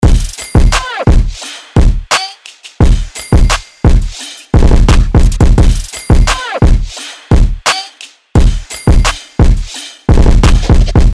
四款高级音色包，共创暗黑风格，专为暗黑街头陷阱音乐和地下节拍打造。
lets_go_perc_loop_173_bpm.mp3